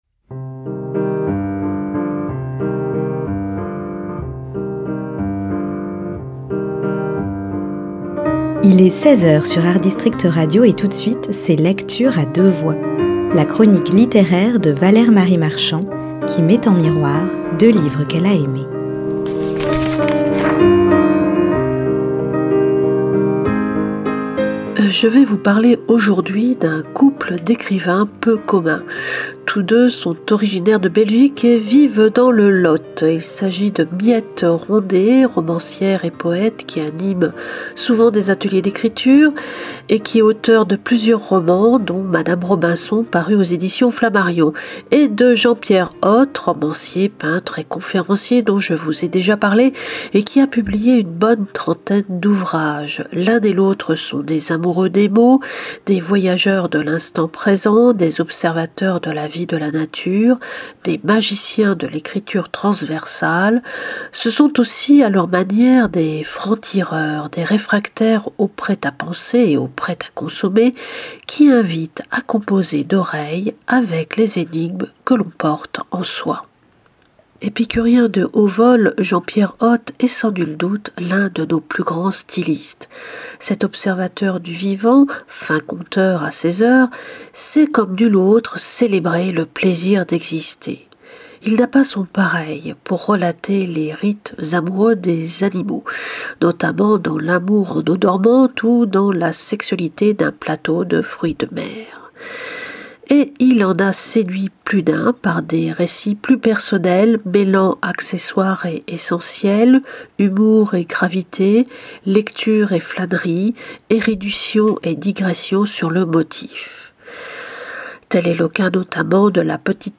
LECTURE A DEUX VOIX, mardi et vendredi à 10h et 16h.